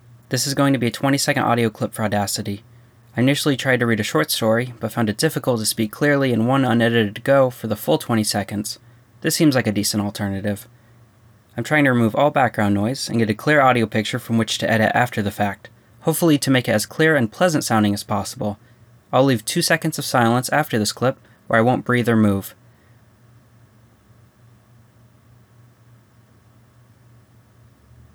It sounds perfectly pleasant to me except for the laundromat in the background.
I applied a Notch Filter to get rid of the one single electric motor tone (120Hz—you’re in the US, right?) and that threw off the ACX Peak reading.
I’m posting the work I got after simple ACX corrections but before Noise Reduction.
I have a laundromat that sounds exactly like that.
Side note: I would have no trouble listening to a story in that voice.
It seems a bit quiet.